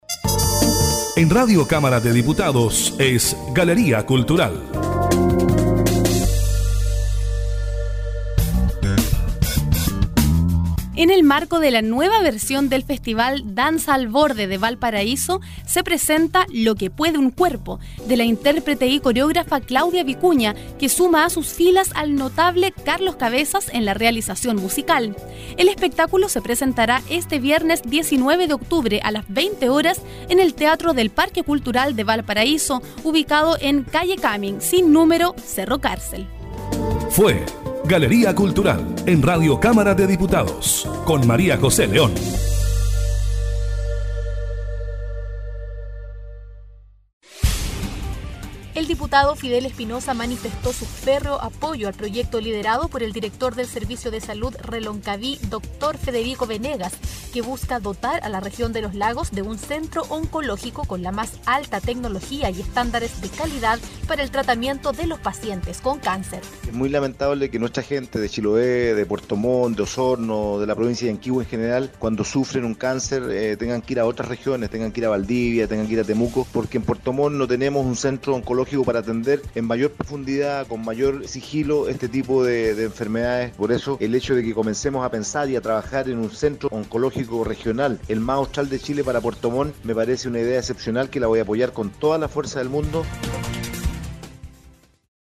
Español Neutro (latino) Noticias Publicidad Voz ancla (anchor voice) E-Learning
Kein Dialekt
Sprechprobe: Sonstiges (Muttersprache):